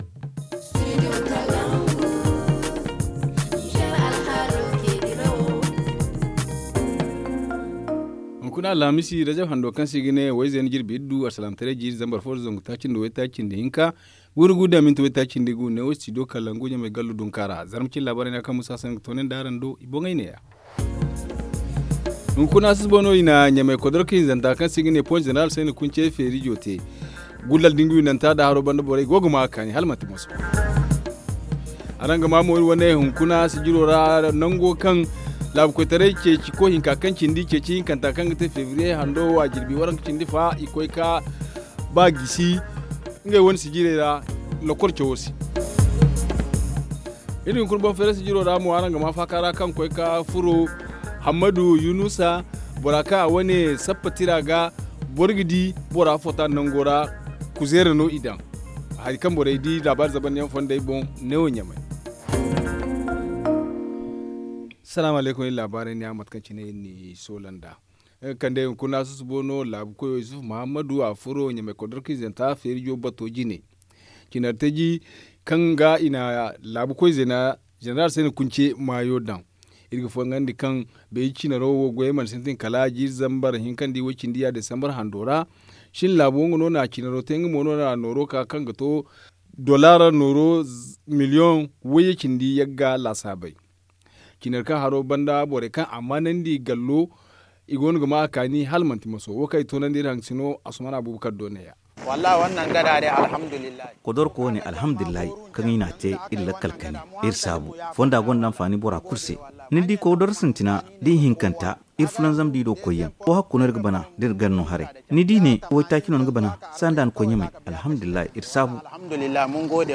Le journal en français